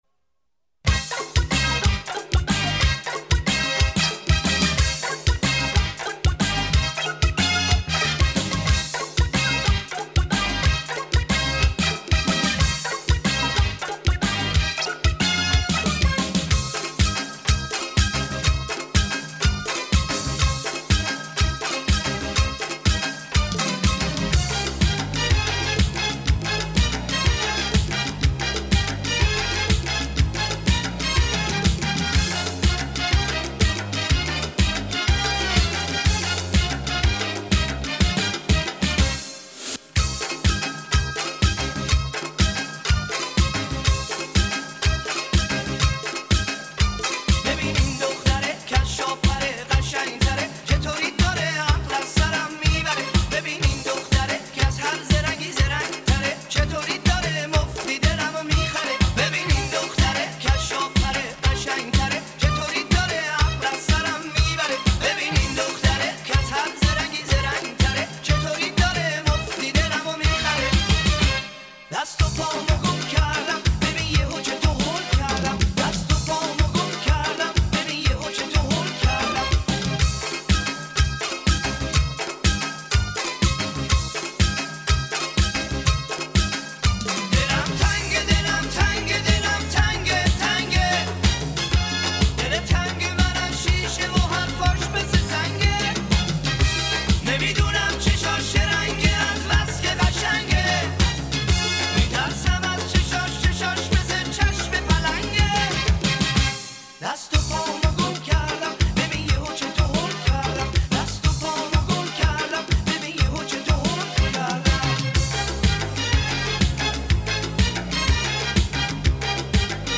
آهنگ شاد ایرانی آهنگ نوستالژی شاد ایرانی